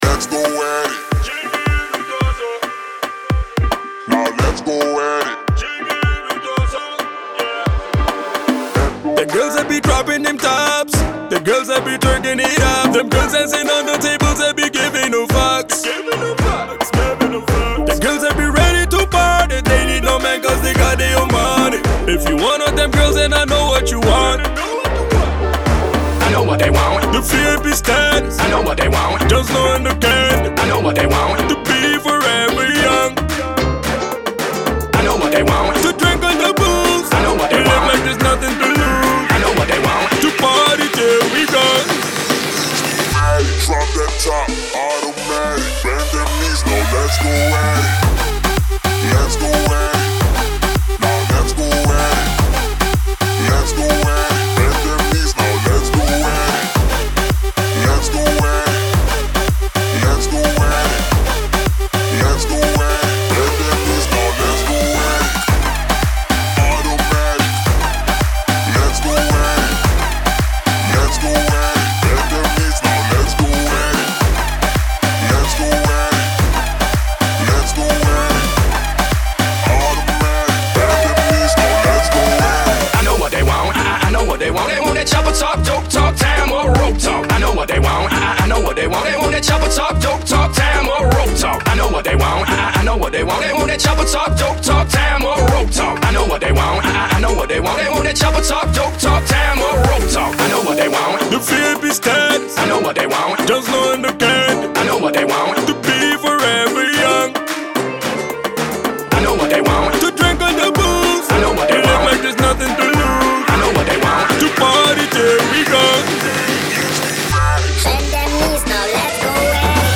энергичная композиция в жанре хип-хоп и поп